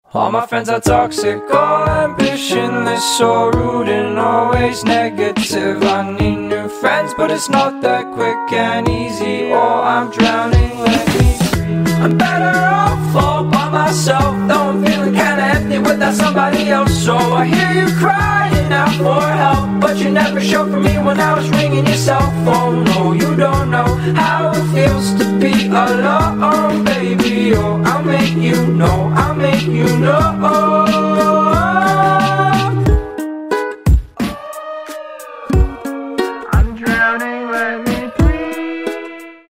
Рингтоны Альтернатива